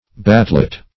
Search Result for " batlet" : The Collaborative International Dictionary of English v.0.48: Batlet \Bat"let\, n. [Bat stick + -let.] A short bat for beating clothes in washing them; -- called also batler , batling staff , batting staff .